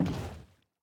Minecraft Version Minecraft Version snapshot Latest Release | Latest Snapshot snapshot / assets / minecraft / sounds / entity / boat / paddle_land4.ogg Compare With Compare With Latest Release | Latest Snapshot
paddle_land4.ogg